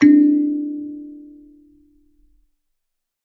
kalimba2_wood-D3-mf.wav